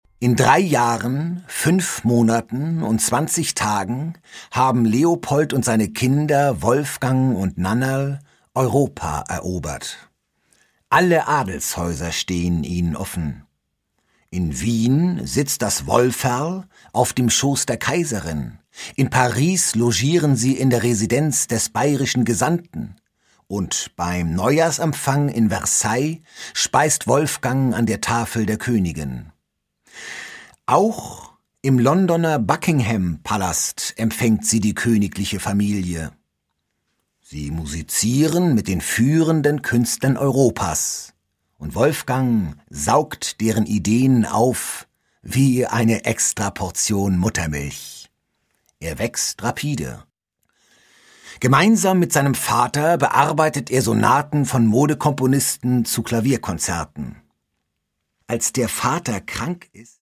Produkttyp: Hörbuch-Download
Fassung: inszenierte Lesung mit Musik